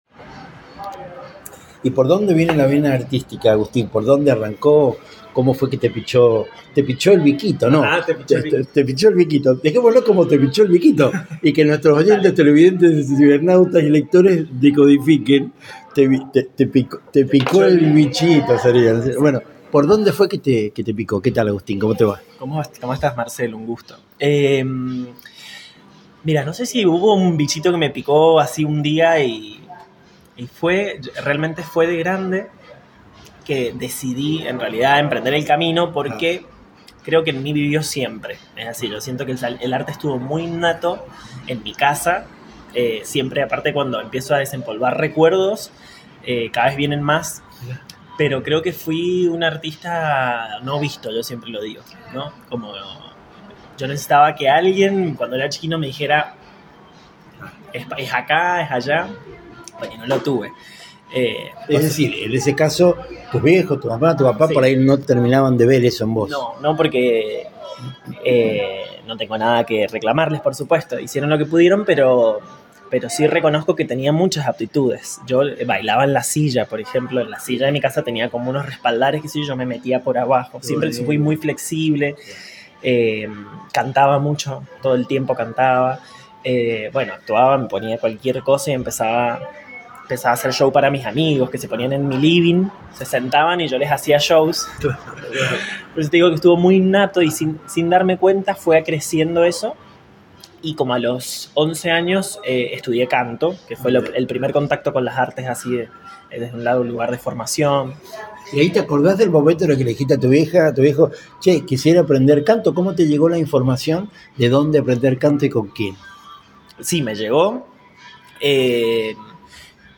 Entrevistas Latinocracia
Esa mañana en el bar, al borde de una taza de café, quedó el registro de un intercambio de enorme profundidad.